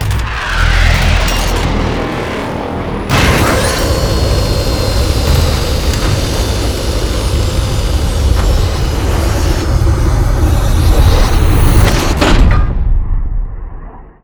land.wav